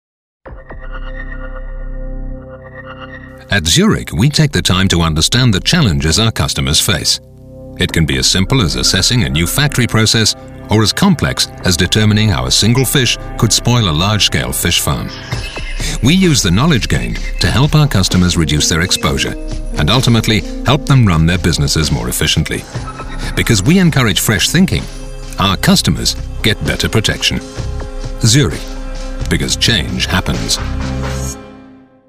Sprecher englisch britisch.
Sprechprobe: eLearning (Muttersprache):
native english / british voice over talent. I am the warm voice of authority specializing in corporate videos, upmarket advertising, e-learning, documentary